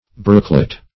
Brooklet \Brook"let\, n.